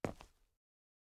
Illusion-UE5/Concrete Walk - 0003 - Audio - Stone 03.ogg at dafcf19ad4b296ecfc69cef996ed3dcee55cd68c